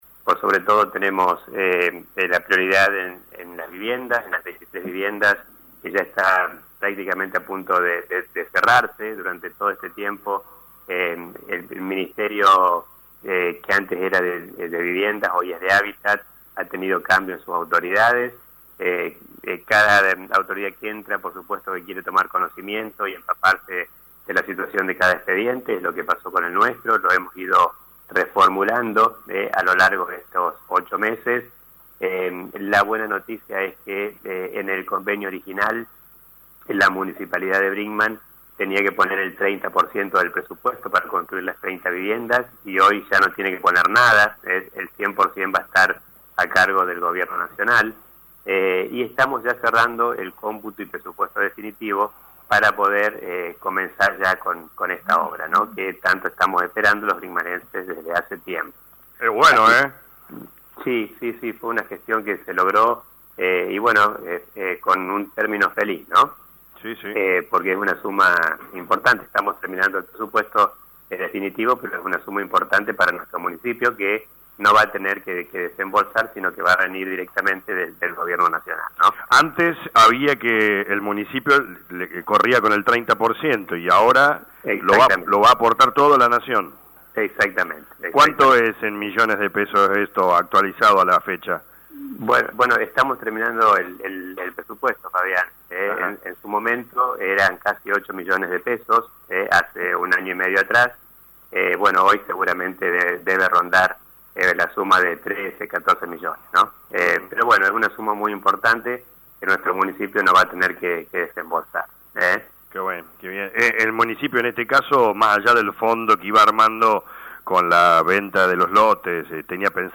En dialogo con LA RADIO 102.9, el Dr. Gustavo Tévez explicó que en el Ministerio de Habitat pudimos dar seguimiento el financiamiento del Plan de 33 viviendas “Techo Digno”, donde en un principio el Municipio tenía que aportar el 30% de la obra total, pero ahora el Gobierno Nacional decidió que se hará cargo del 100% de la obra de las 33 viviendas, por lo que el aporte de 13 o 14 millones que tenía que hacer el Municipio lo aportará la Nación”.